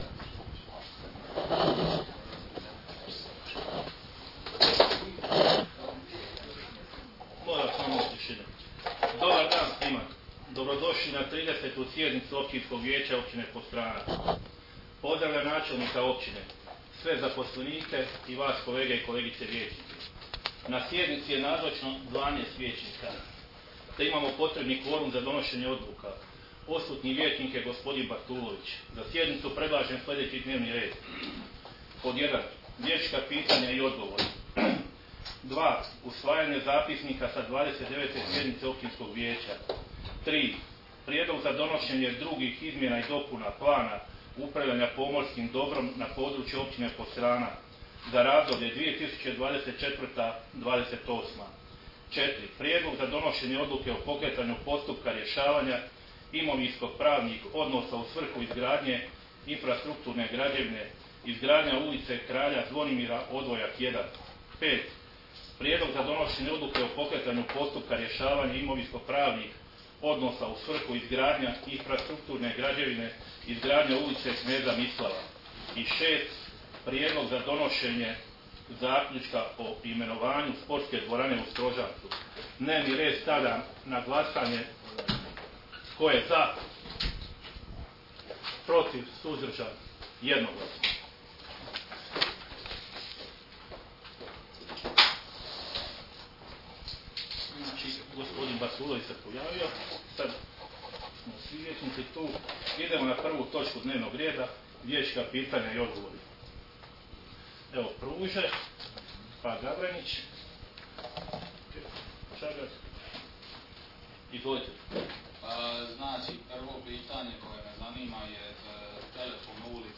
Sjednica će se održati dana 04. srpnja (četvrtak) 2024. godine u 19,00 sati u Vijećnici Općine Podstrana.